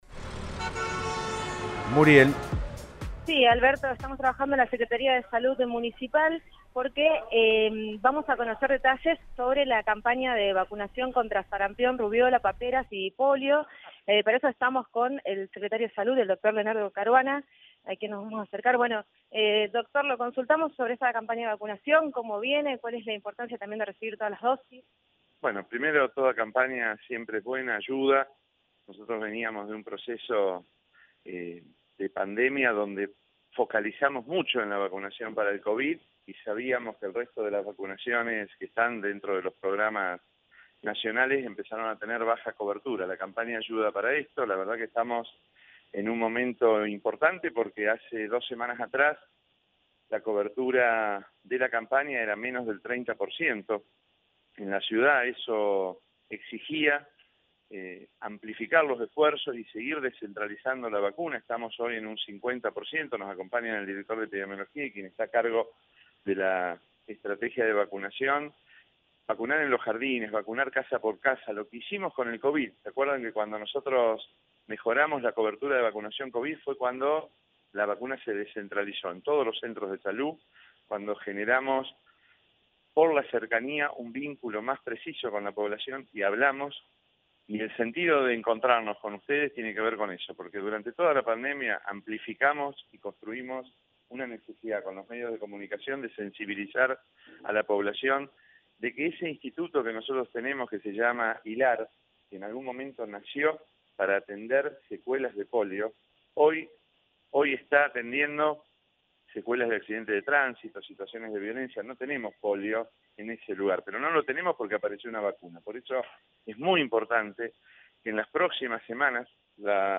Hace dos semanas atrás la cobertura de la campaña era menor al 30 por ciento en la ciudad. Hoy estamos en un 50 por ciento”, dijo Caruana en diálogo con el móvil de Cadena 3 Rosario, en Siempre Juntos.